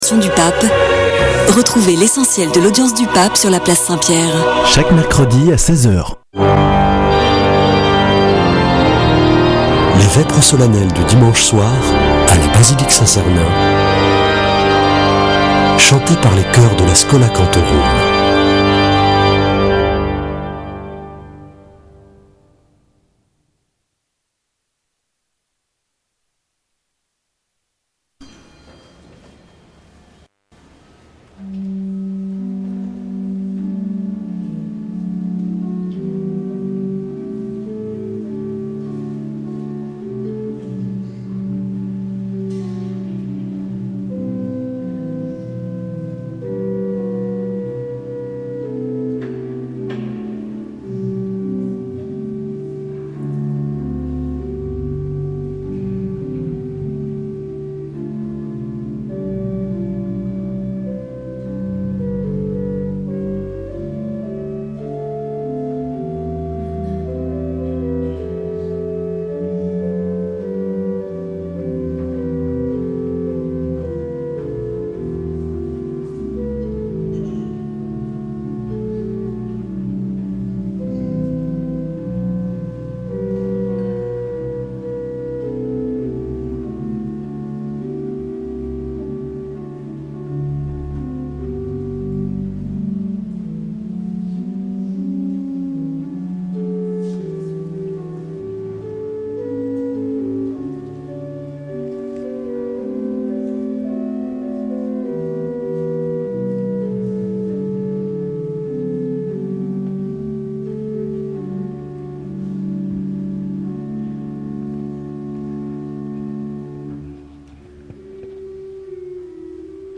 Vêpres de Saint Sernin du 29 déc.
Une émission présentée par Schola Saint Sernin Chanteurs